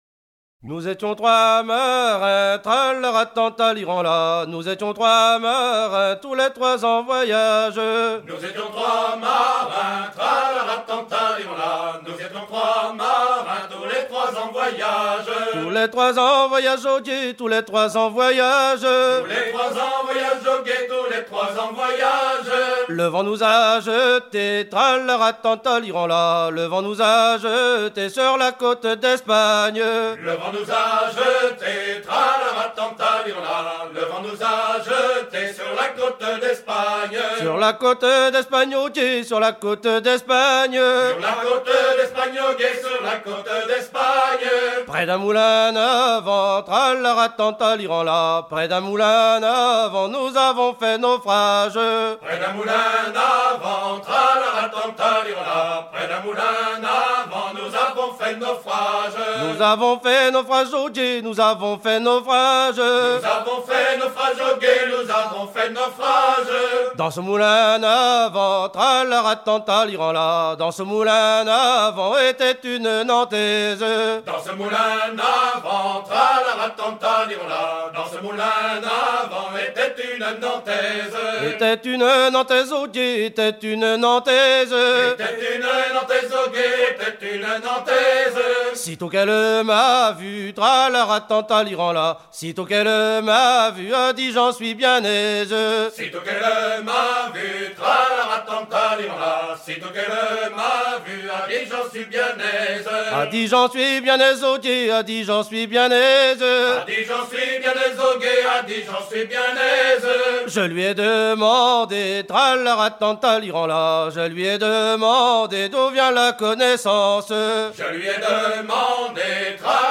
danse : ronde
Genre laisse
Pièce musicale éditée